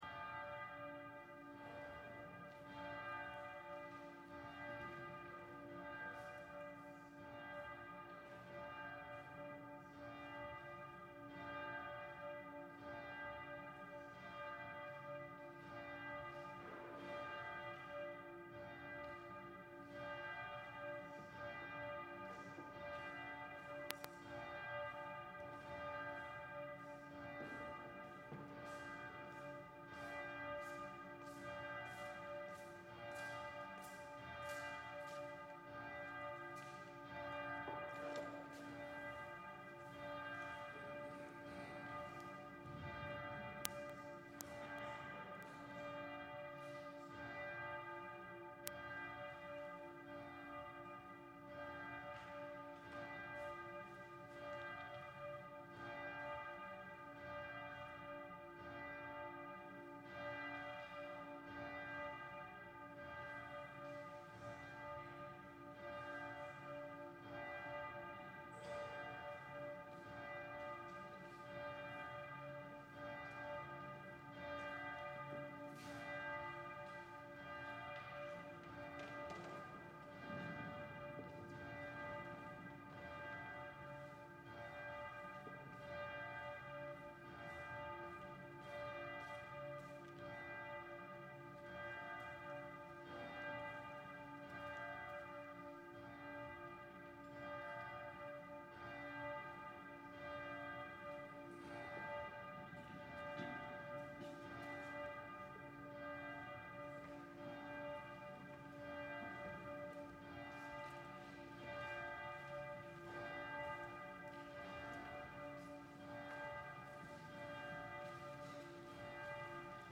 The Divine Office, chanted by the monks of the Abbaye Sainte-Madeleine du Barroux.
Gregorian